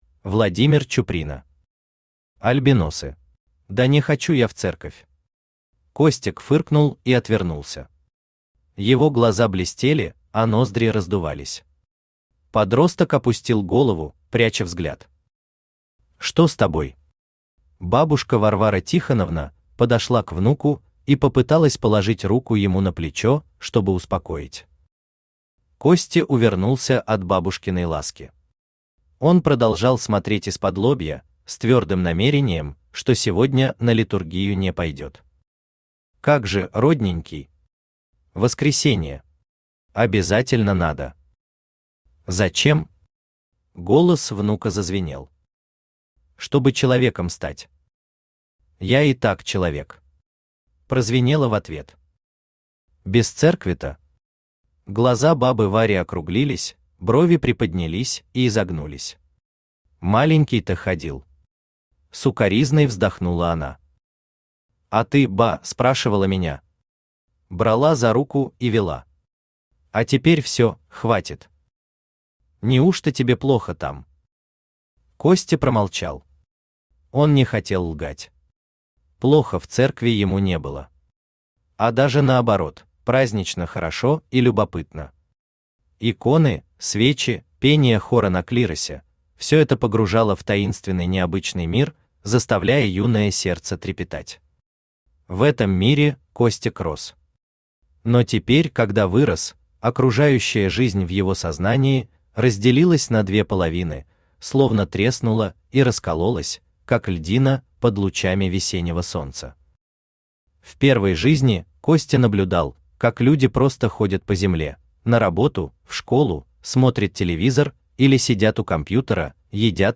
Аудиокнига Альбиносы | Библиотека аудиокниг
Aудиокнига Альбиносы Автор Владимир Иванович Чуприна Читает аудиокнигу Авточтец ЛитРес.